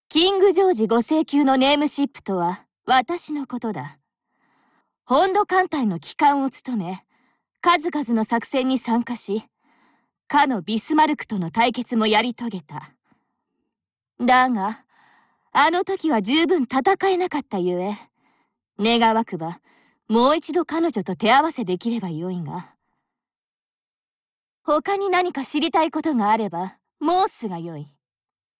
川澄 绫子 / かわすみ あやこ / Ayako Kawasumi / 事务所个人介绍页
舰船台词